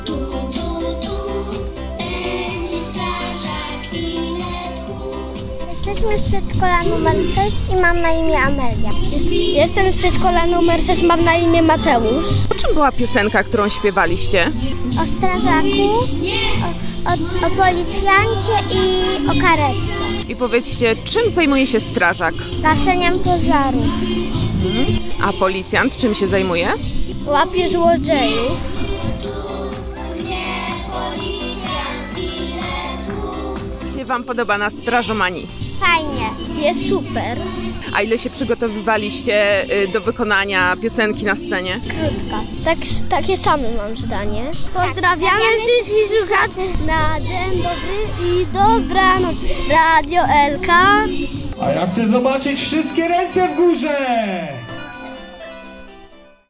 Polkowice dają przykład, jak uczyć dzieci bezpieczeństwa. W czwartek (12.05) w Centralnym Parku Zabaw i Wypoczynku odbyła się "Strażomania".
Dzieci śpiewały piosenki tematycznie związane z bezpieczeństwem i uczestniczyły w konkurencjach wymagających od nich znajomości zasad zachowania się w trudnych sytuacjach.